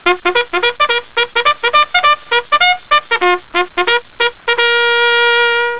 Cŕŕn Zvuk polnej trúbky vyhlasuje budíček 0:05